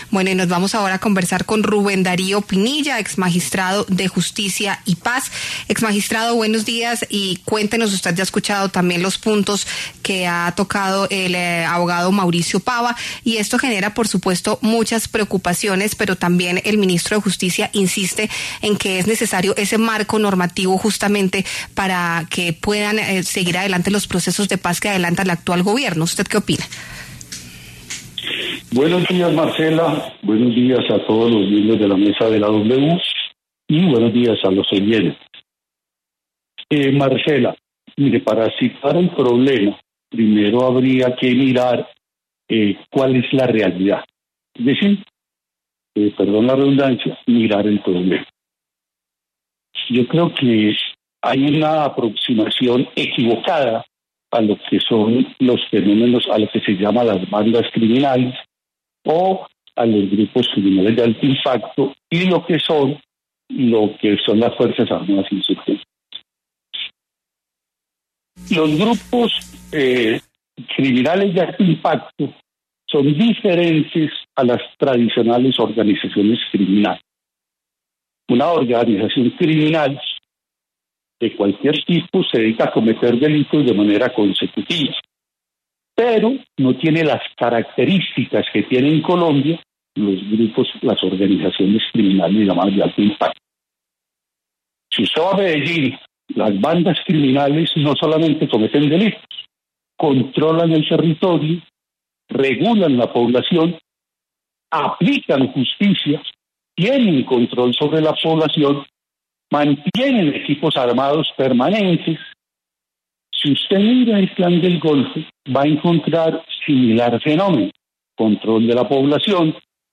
En diálogo con La W, el exmagistrado de la Sala de Justicia y Paz, Rubén Darío Pinilla Cogollo, se refirió al borrador del proyecto del Ministerio de Justicia que será presentado por el Gobierno ante el Congreso de la República para aplicar la “paz total” y su marco jurídico.